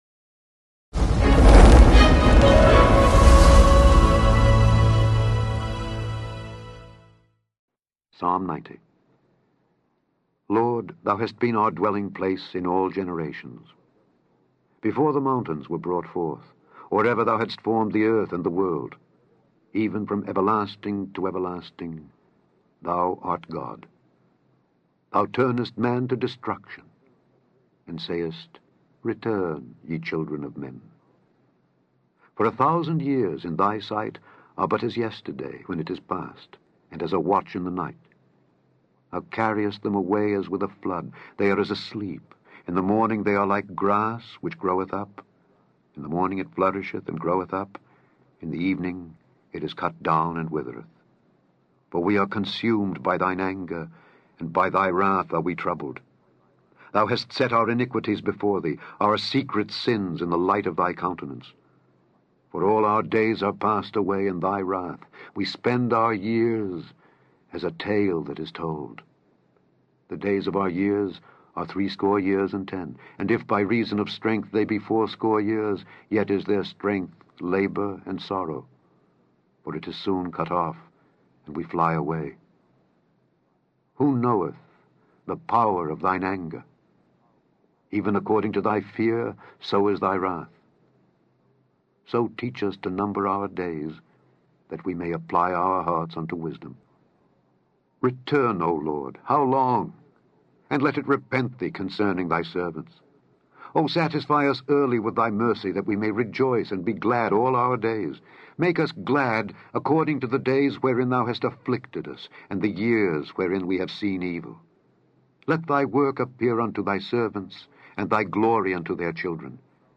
Daily Bible Reading: Psalms 90-92
Click on the podcast to hear Alexander Scourby read Psalms 90-92.